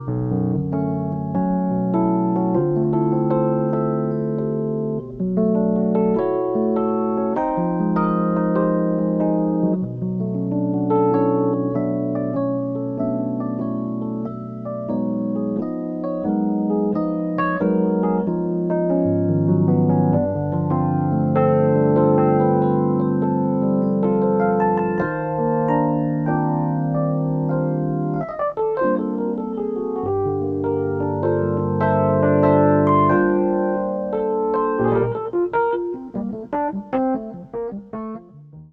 Fender Rodes electric piano